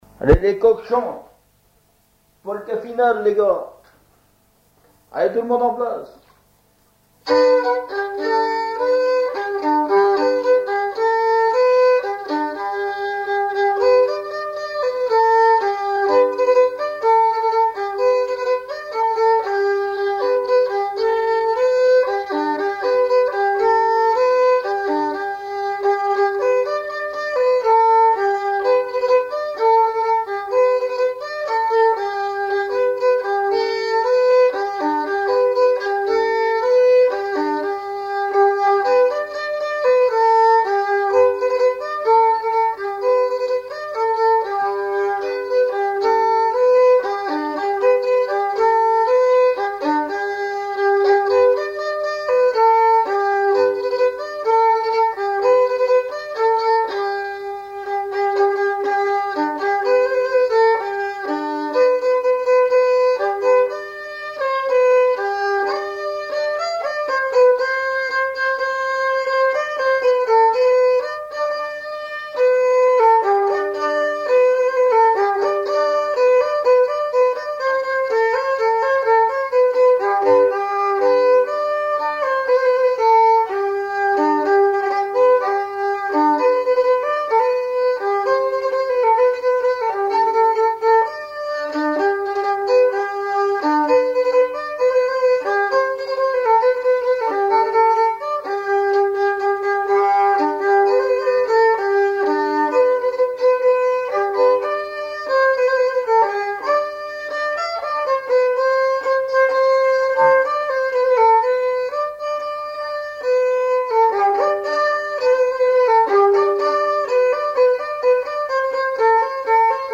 Note pot-pourri
Saint-Vincent-Sterlange
danse : polka
Auto-enregistrement
Pièce musicale inédite